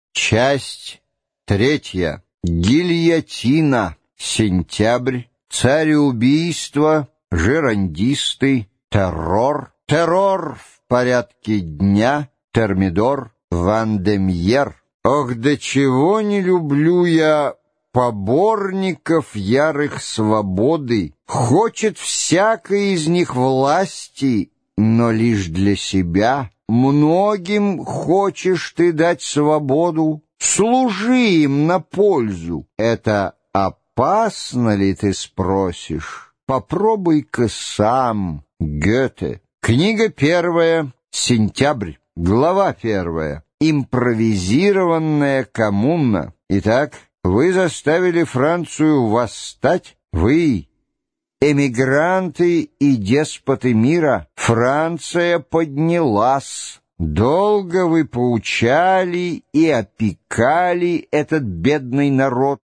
Аудиокнига Французская революция. История. Часть 3. Гильотина | Библиотека аудиокниг